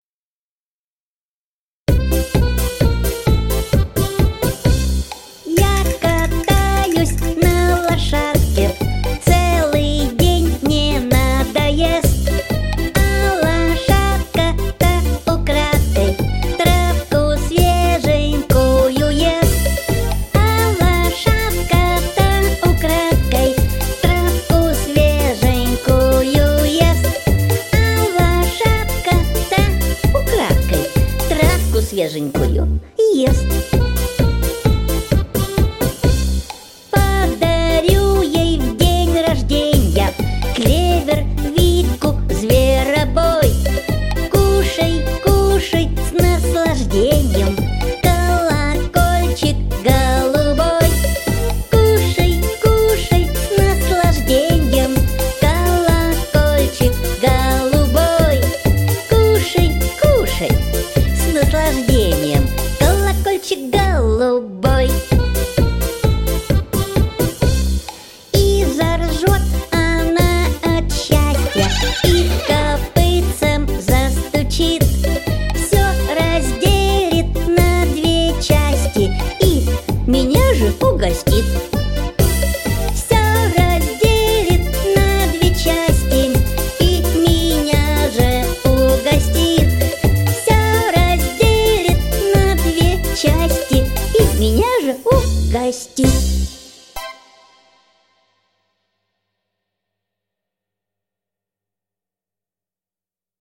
• Категория: Детские песни